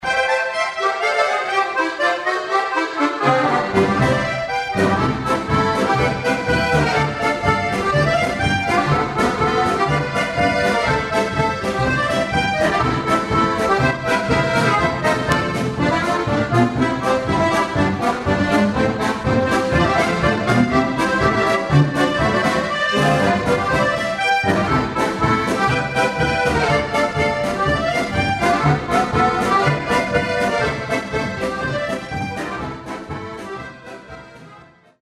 Schweizer Volksmusik, Spirituelle Musik
* Akkordeon (in unseren Breitengraden auch Handorgel genannt)
* Schlagzeug
* Perkussion
* Klavier